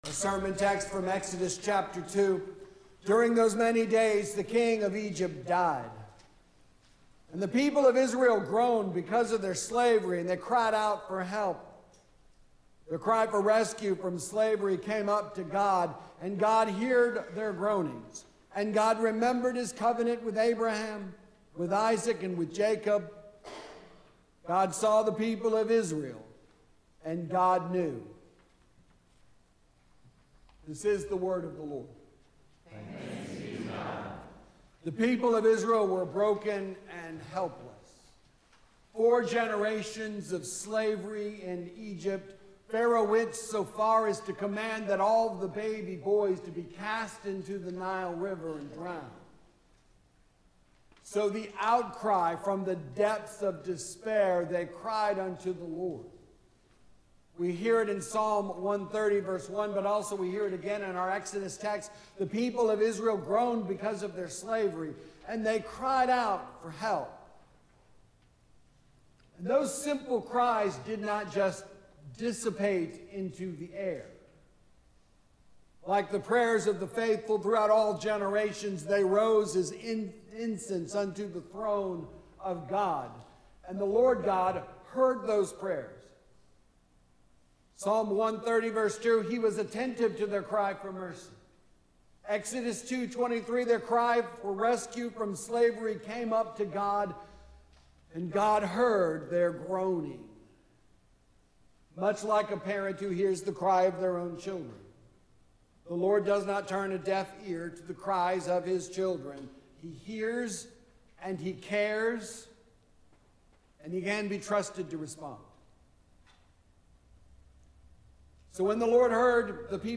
Sermon-for-Ash-Wednesday.mp3